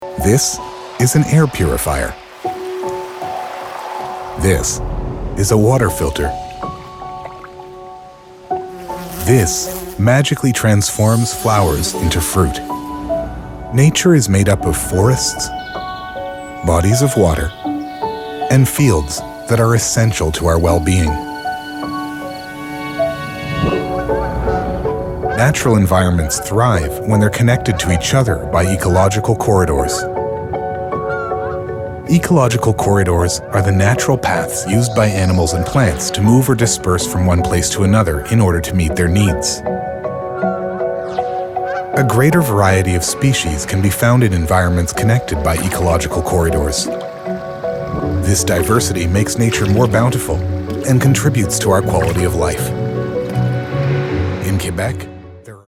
deep, versatile Canadian English voice – from gritty and authoritative to upbeat and playful
Narration
UAD Apollo Twin, Neumann U87, Audio Technica AT 2050, Avid Pro Tools, Izotope Suite, Waves Suite, Logic Pro.
BaritoneBassDeep